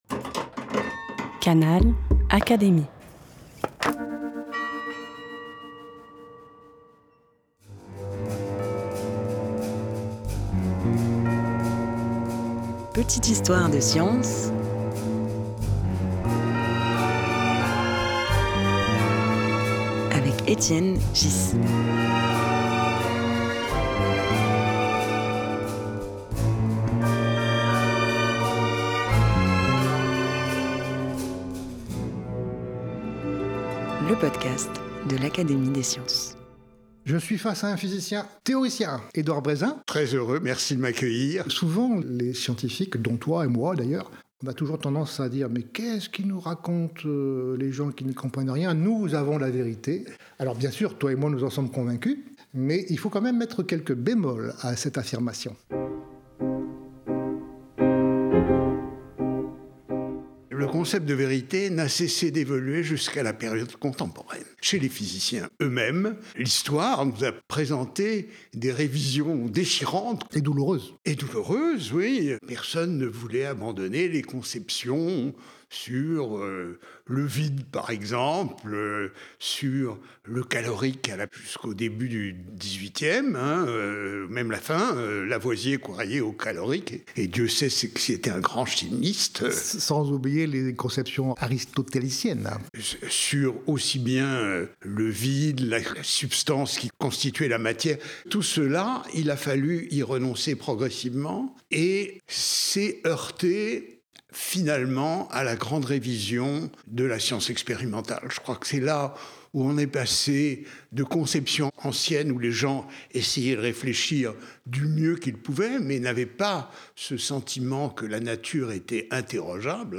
Dans cet épisode, le physicien Édouard Brézin retrace, aux côtés d’Étienne Ghys, l’histoire de la vérité scientifique.
Un podcast animé par Étienne Ghys, proposé par l'Académie des sciences.